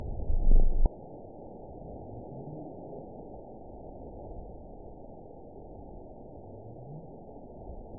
event 912526 date 03/28/22 time 21:16:54 GMT (3 years, 1 month ago) score 9.68 location TSS-AB04 detected by nrw target species NRW annotations +NRW Spectrogram: Frequency (kHz) vs. Time (s) audio not available .wav